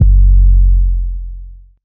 808s